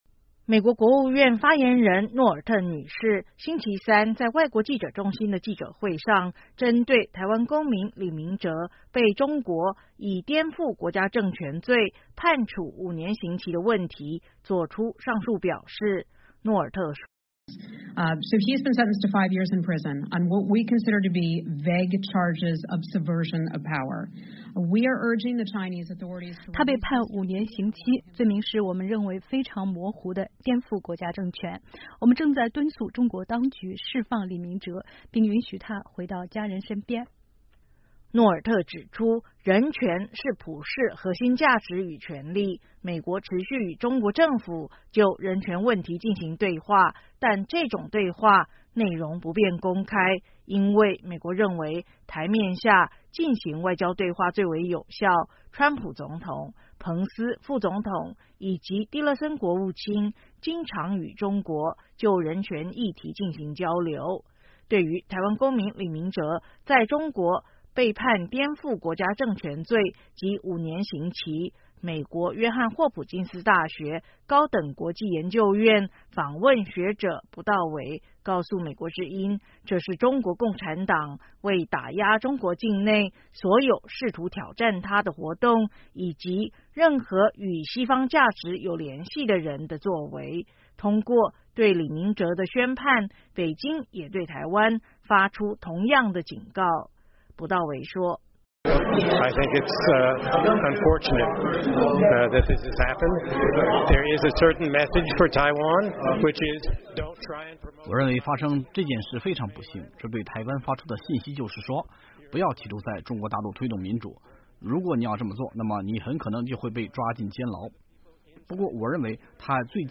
美国国务院发言人希瑟·诺尔特(Heather Nauert)在记者会上(2017年10月4日)